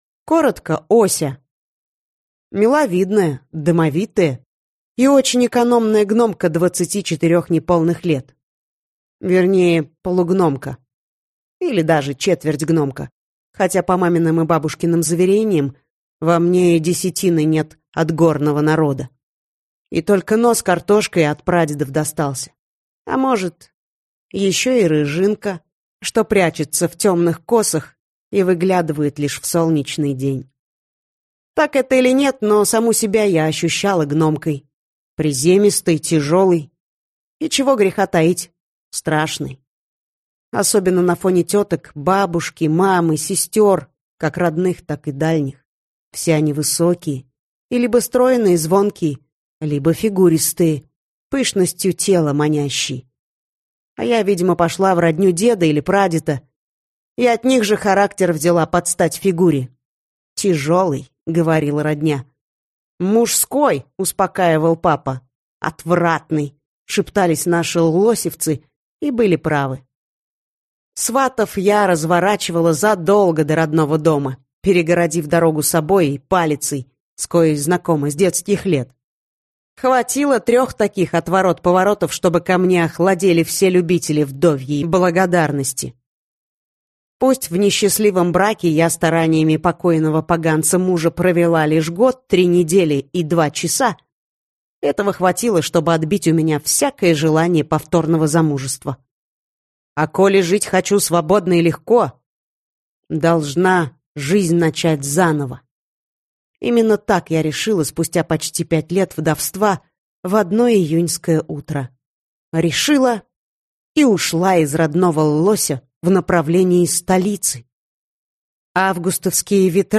Аудиокнига Гномка в помощь, или Ося из Ллося - купить, скачать и слушать онлайн | КнигоПоиск